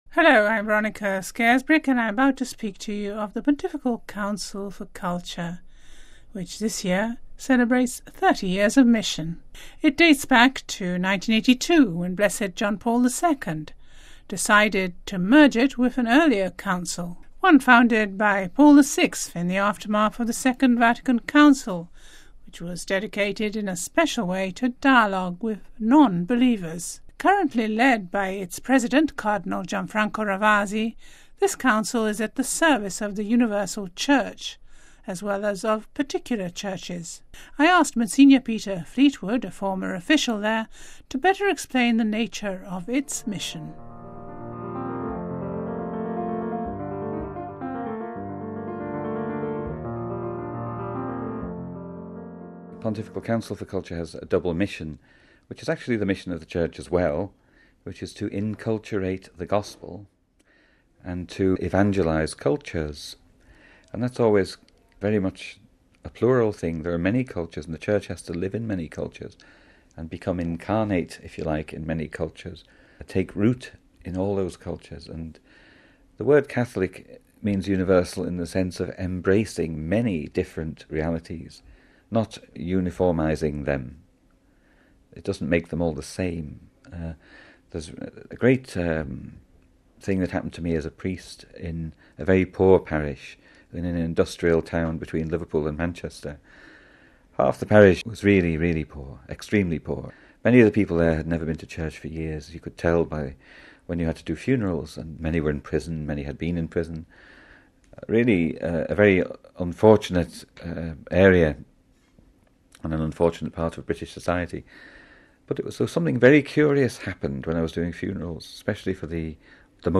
(Vatican Radio )